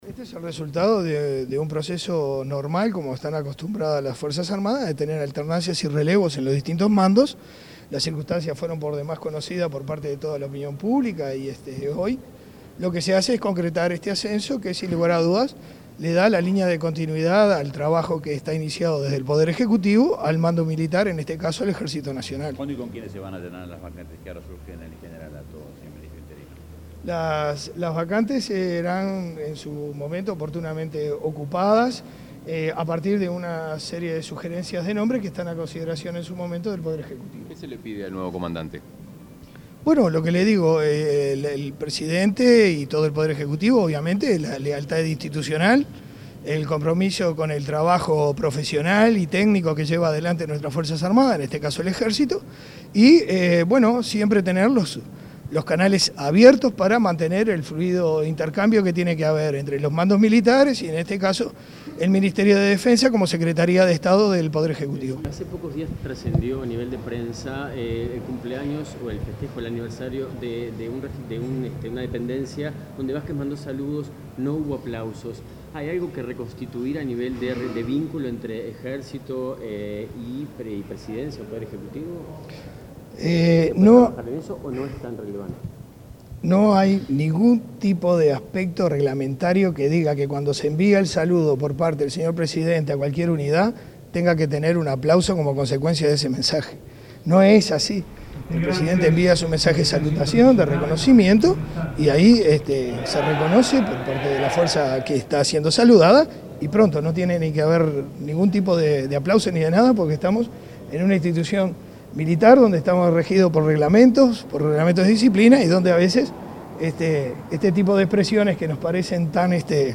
El nombramiento de José Ariel González como comandante en jefe del Ejército Nacional forma parte de las alternancias y relevos de los distintos mandos de las Fuerzas Armadas, señaló este lunes 18 el ministro interino de Defensa, Daniel Montiel, tras la ceremonia de asunción. Añadió que la designación de González se realizó luego de considerar diversos aspectos de su trayectoria profesional y carácter personal.